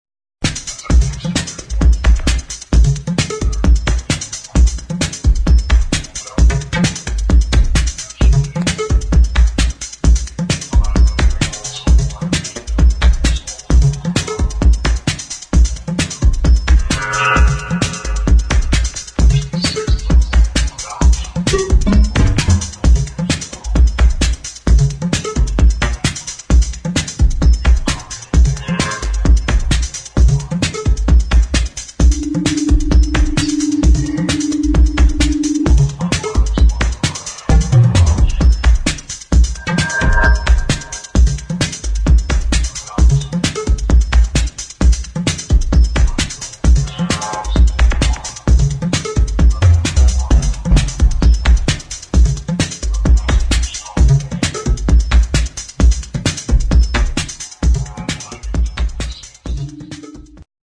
[ TECHNO / ELECTRO ]